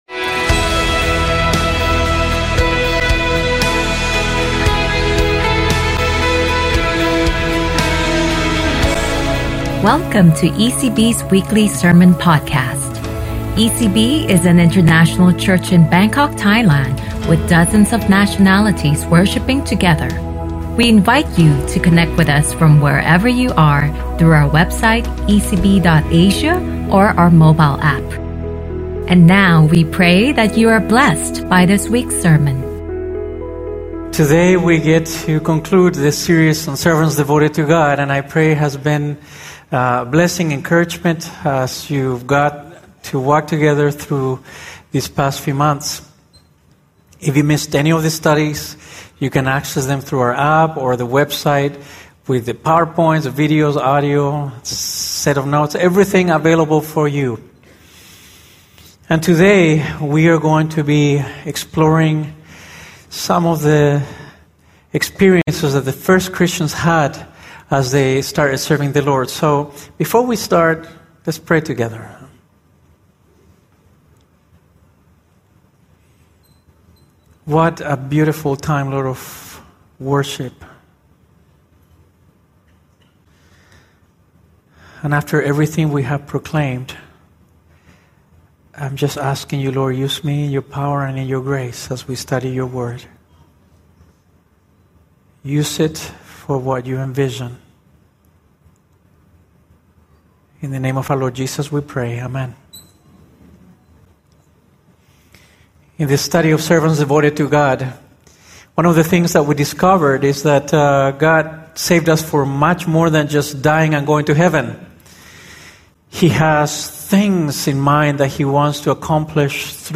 ECB Sermon Podcast